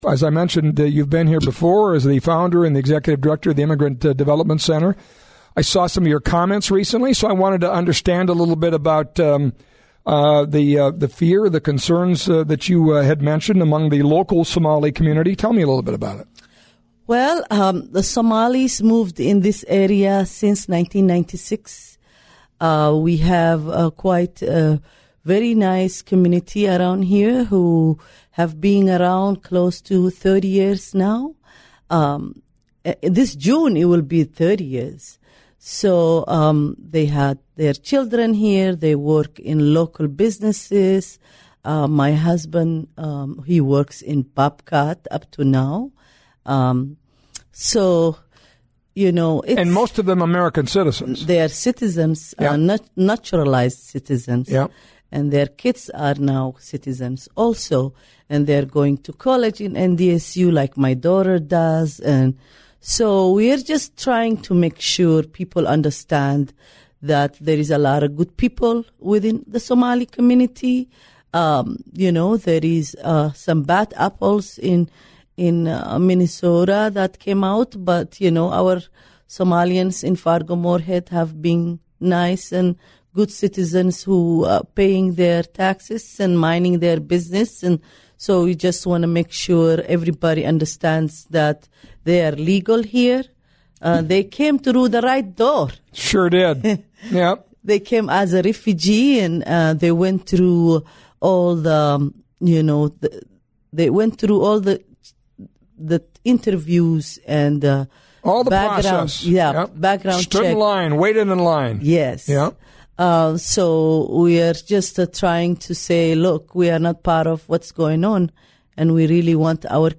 conversation